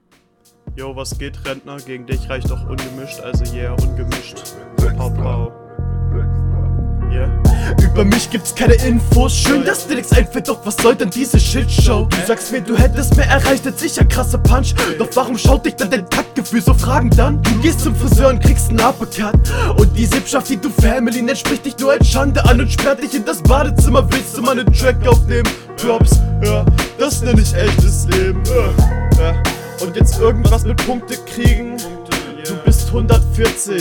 Besser gerappt, auch wenn ungemischt ein bisschen seeehr raw und hier auch Stimmeinsatz & Pausensetzung …